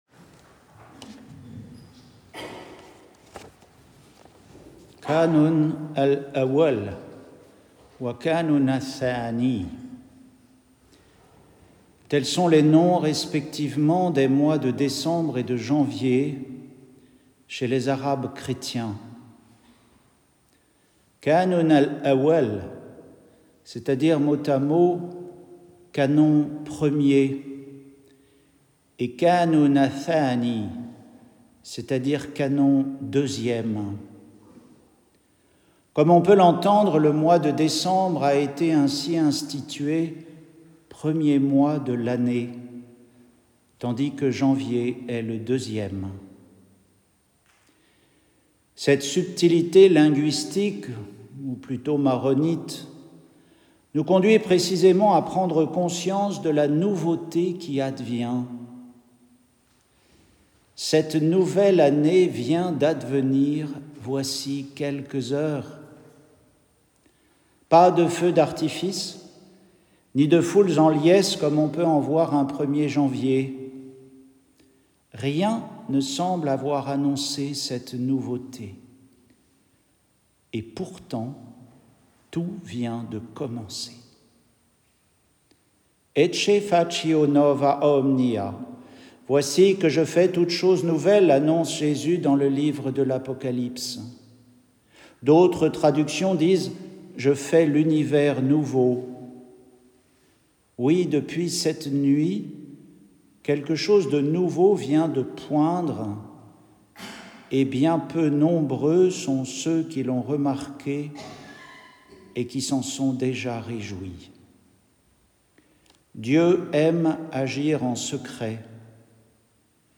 Retrouvez les méditations d’un moine sur les lectures de la messe du jour.
Homélie pour le 1er dimanche d’Avent